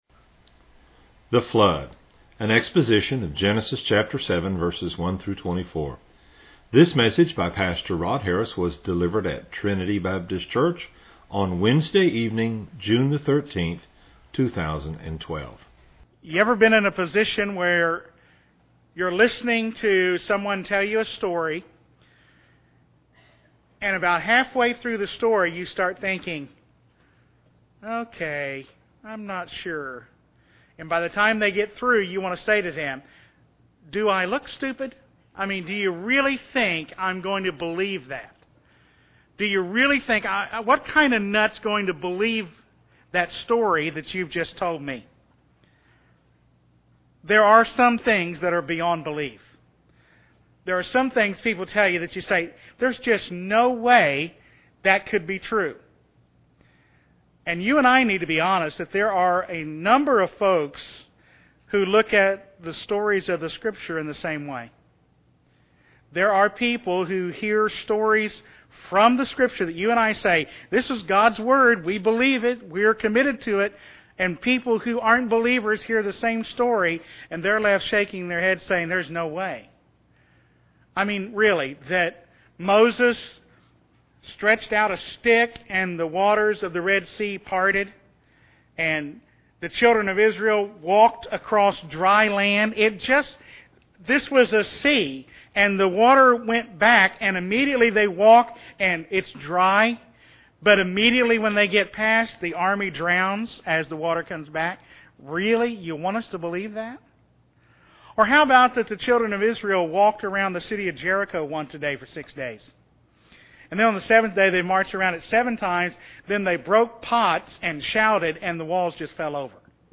delivered at Trinity Baptist Church on Wednesday evening